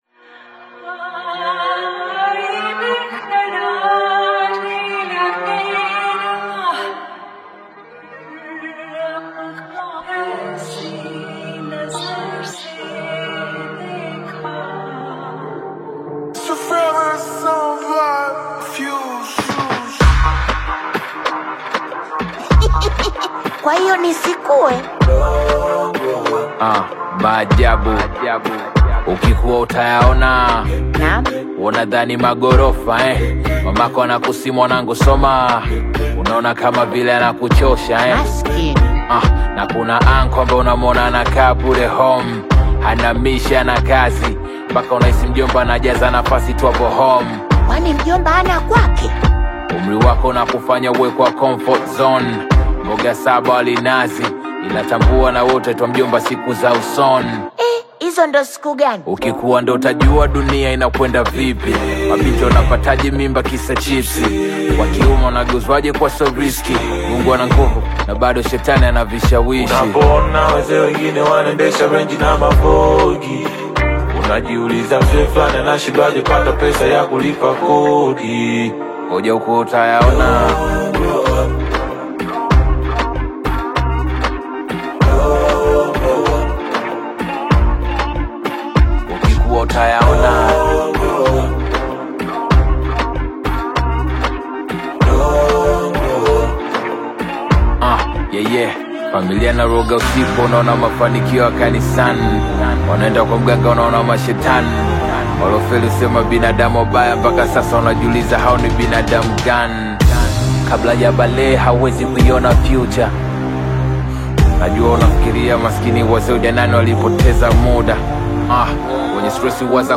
catchy Bongo Flava/Afro-Pop single